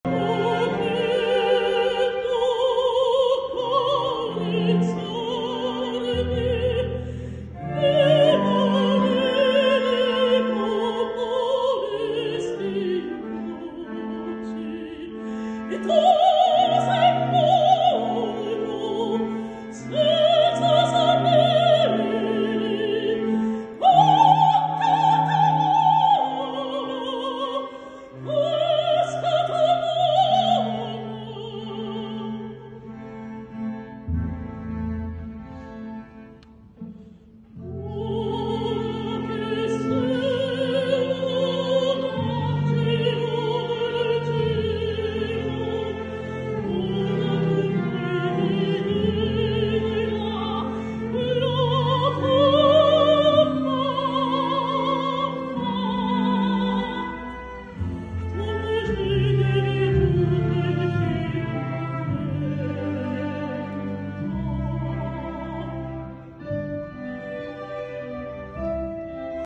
EXTRAITS D'ENREGISTREMENTS EN PUBLIC